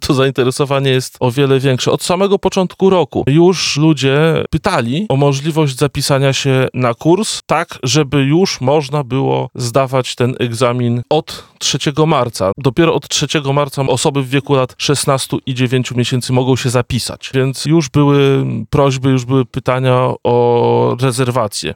Porannej Rozmowie Radia Centrum